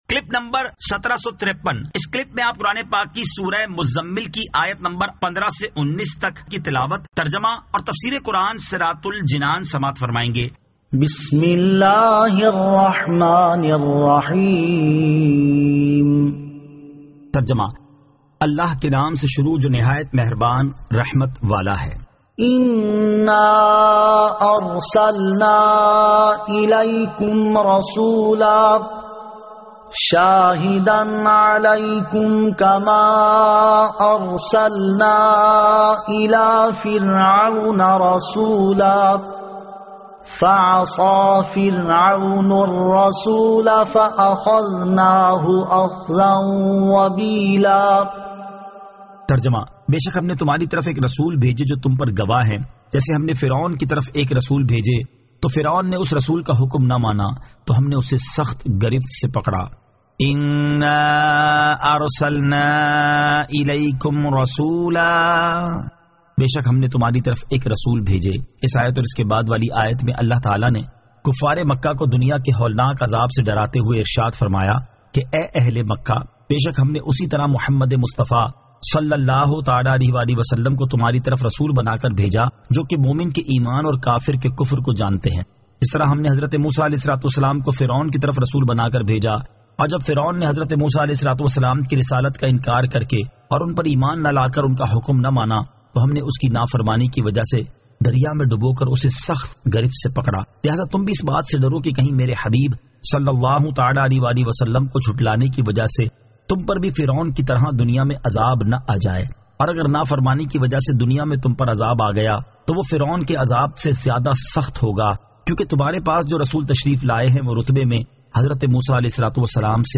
Surah Al-Muzzammil 15 To 19 Tilawat , Tarjama , Tafseer